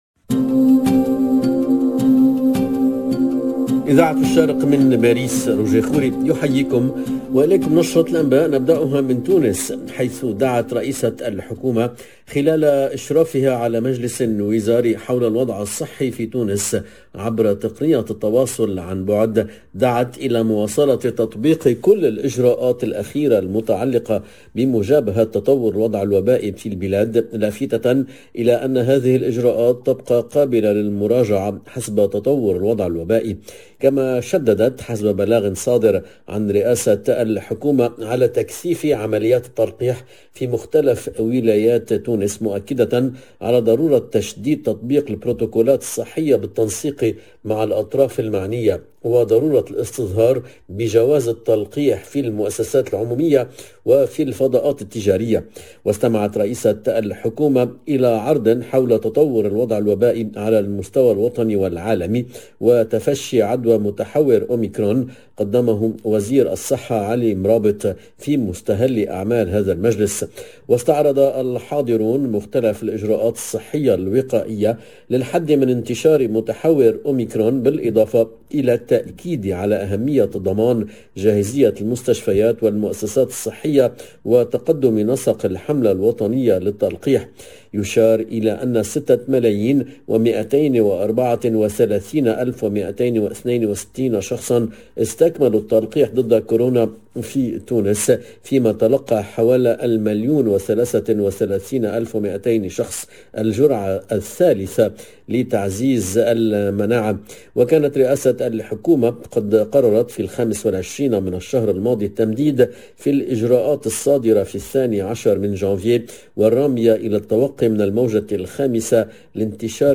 LE JOURNAL DE MIDI 30 EN LANGUE ARABE DU 2/02/22